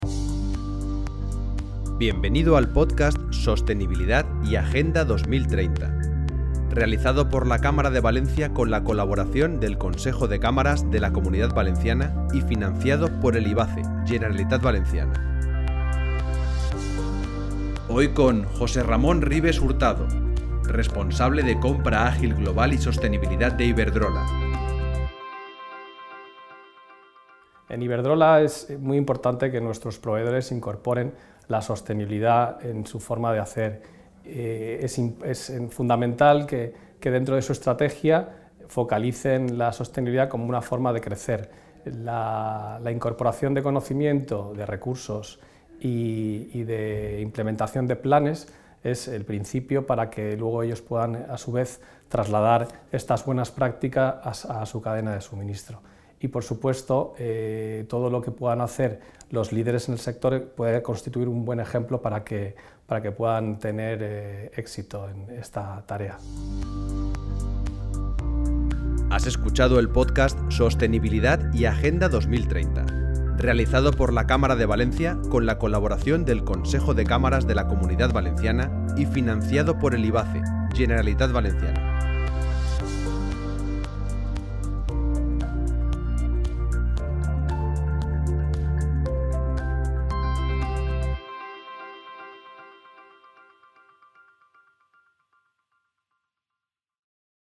Con el podcast “La revolución 4.0” pretendemos realizar una foto desde dentro de las propias compañías, entrevistando a los CEO, directivos y/o responsables de la transformación digital de un gran número de empresas de Valencia, algunas de la Comunidad Valencia y también del territorio nacional.